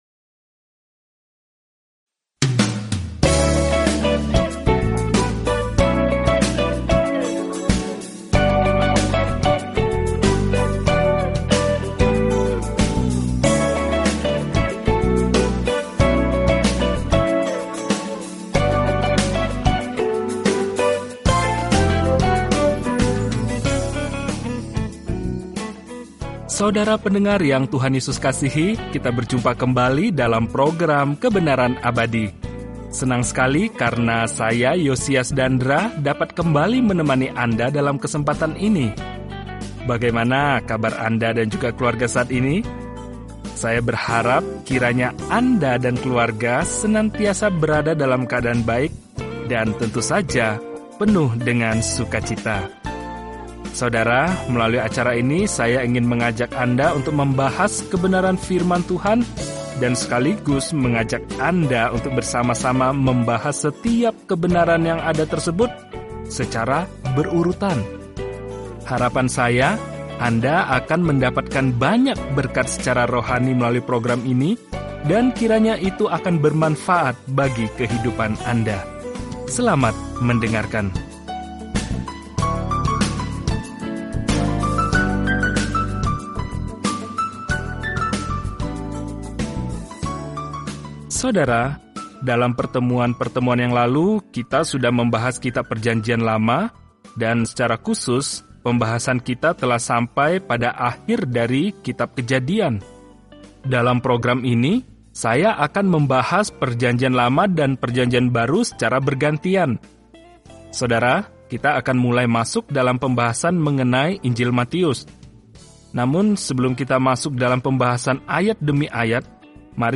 Firman Tuhan, Alkitab Matius 1:1 Mulai Rencana ini Hari 2 Tentang Rencana ini Matius membuktikan kepada para pembaca Yahudi kabar baik bahwa Yesus adalah Mesias mereka dengan menunjukkan bagaimana kehidupan dan pelayanan-Nya menggenapi nubuatan Perjanjian Lama. Telusuri Matius setiap hari sambil mendengarkan studi audio dan membaca ayat-ayat tertentu dari firman Tuhan.